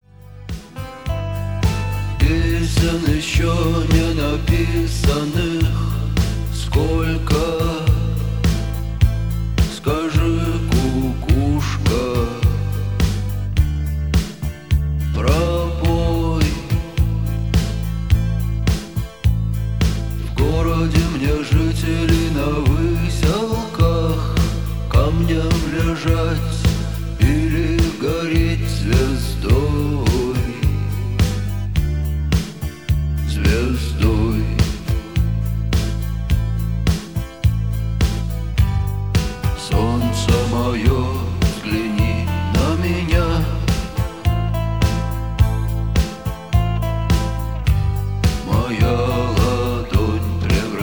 • Качество: 320, Stereo
душевные
спокойные
русский рок